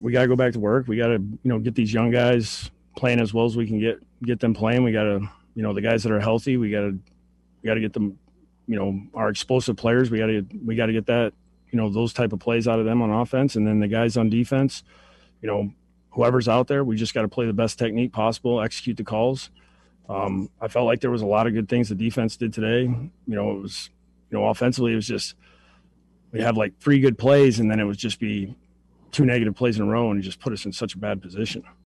After the loss, Gase said the team’s success will be dependent on getting their younger players more involved.